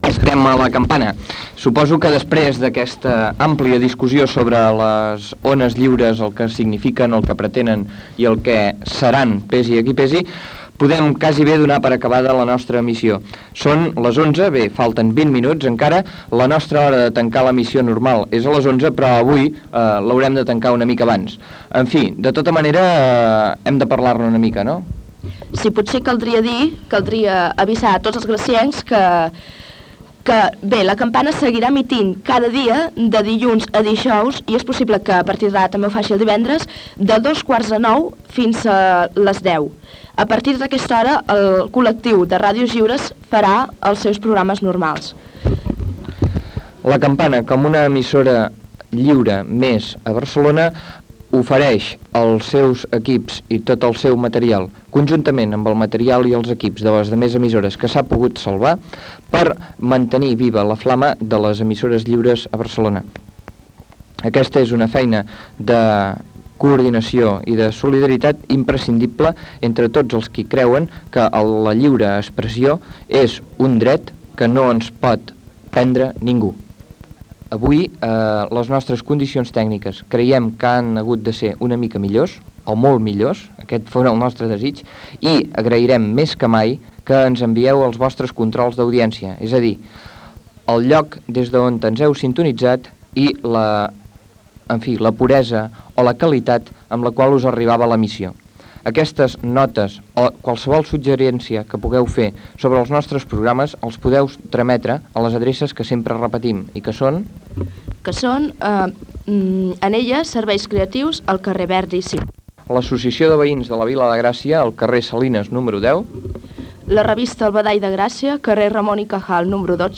Informatiu
FM
Tancament del primer dia d'emissió conjunta de totes les ràdios lliures des dels estudis de La Campana després del quart tancament d'Ona Lliure dos dies abans.